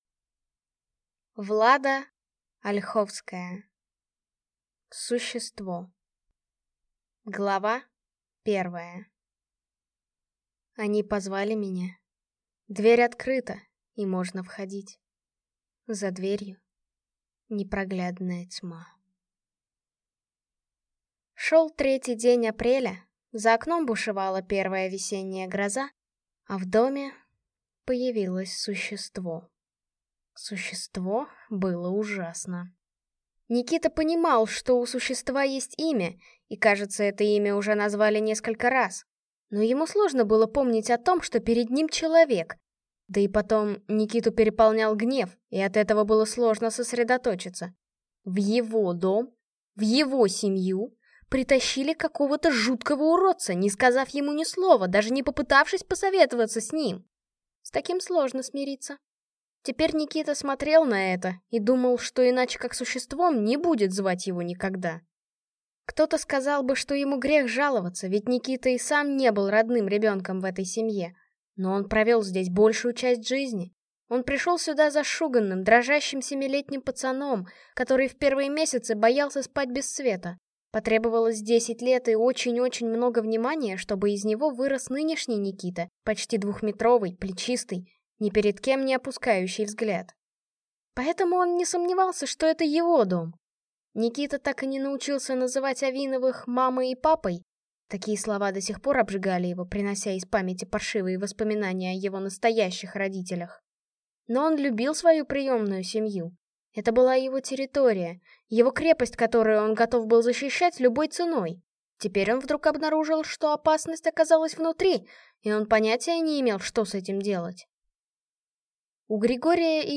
Аудиокнига Существо | Библиотека аудиокниг